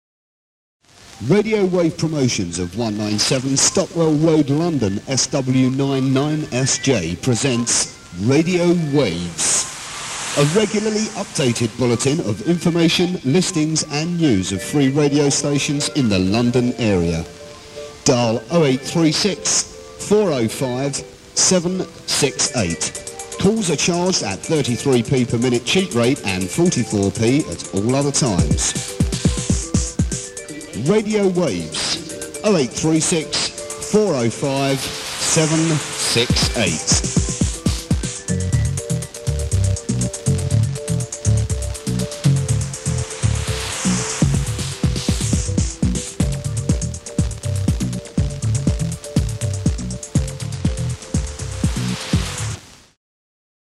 (quality a bit sus' on some tapes)